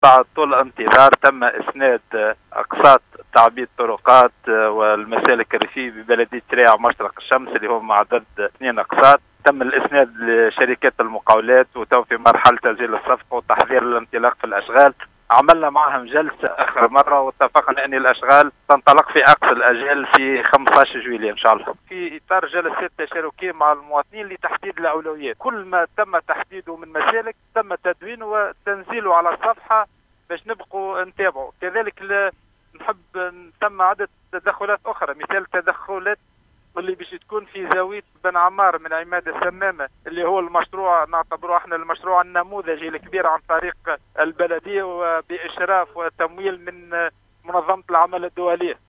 قال رئيس بلدية الشرائع مشرق الشمس الناجم الصالحي في تصريح لإذاعة السيليوم أف أم ببرنامج القصرين و أحوالها اليوم الثلاثاء 21 جوان 2022 أنّه سيتم إسناد أقساط تعبيد طرقات و مسالك ريفيّة ببلديّة الشّرايع مشرق الشّمس ، و قد تمّ الإسناد لشركات المقاولات و الآن هم في مرحلة تسجيل الصفقة و التّحضير لانطلاق الأشغال التي ستنطلق في 15 من جويلية القادم .